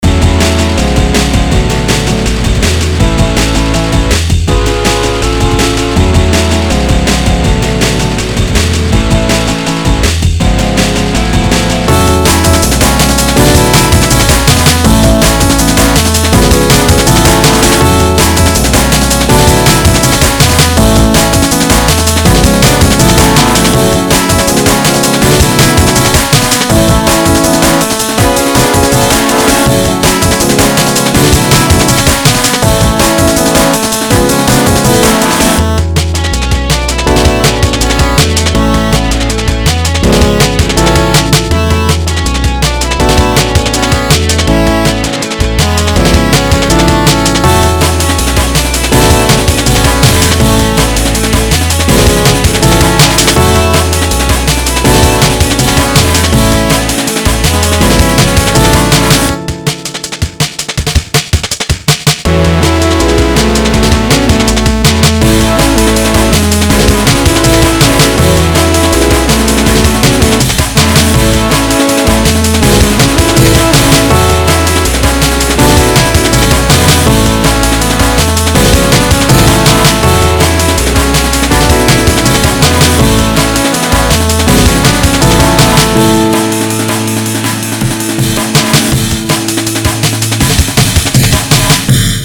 electronic electricguitar amenbreak drums